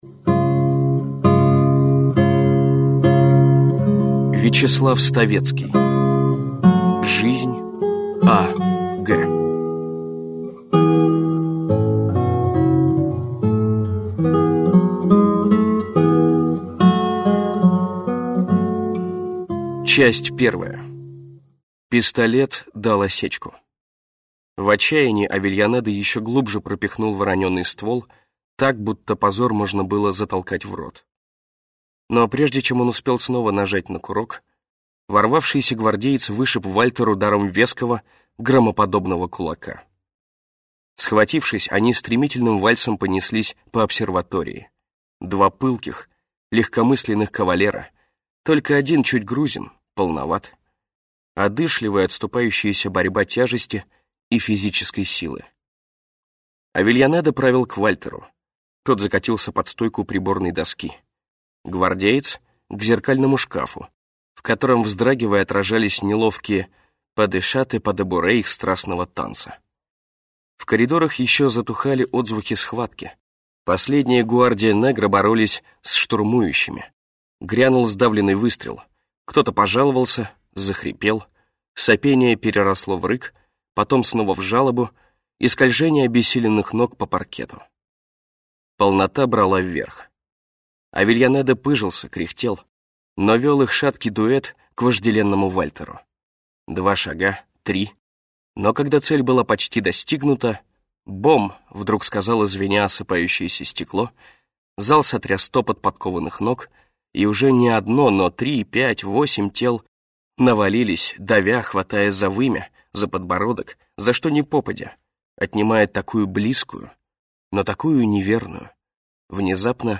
Аудиокнига Жизнь А.Г. | Библиотека аудиокниг